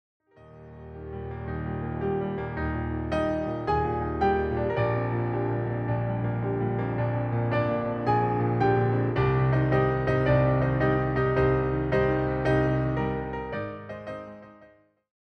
translate to a solo piano setting.